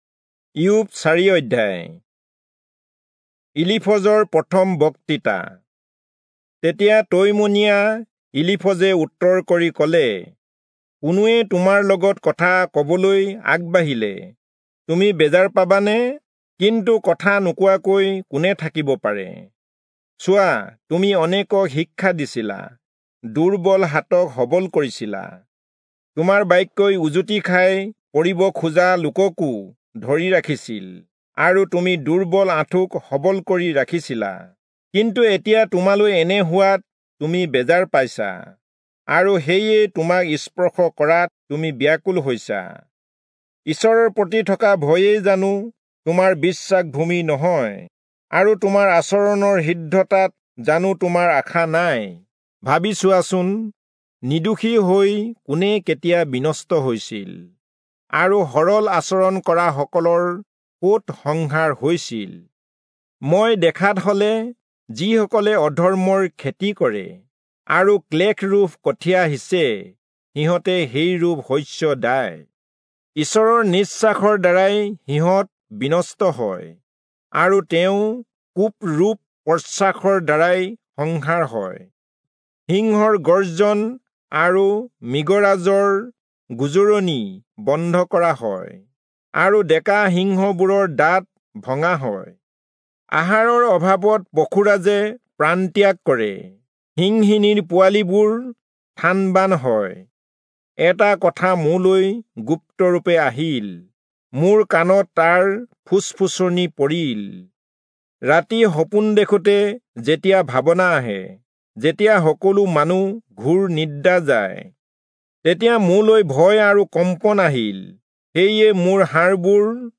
Assamese Audio Bible - Job 10 in Ocvhi bible version